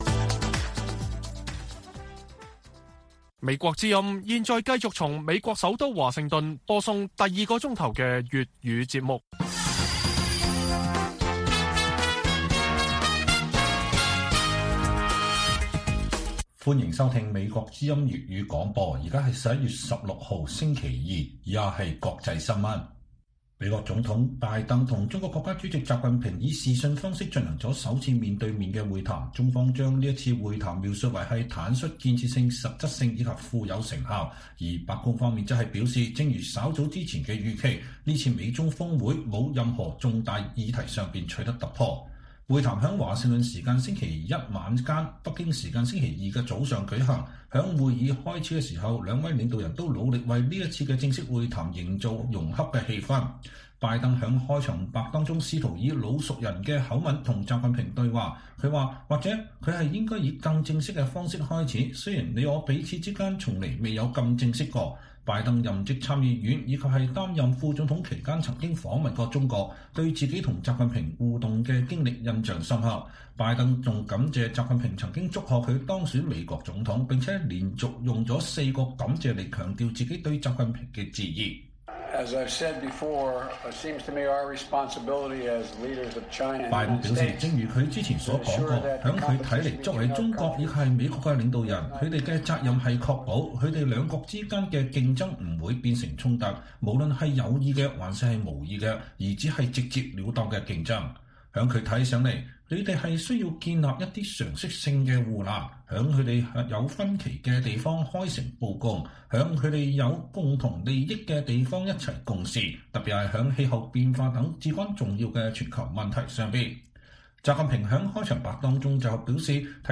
粵語新聞 晚上10-11點： 美中首腦首次正式視訊會晤中方稱“富有成效”白宮說沒有突破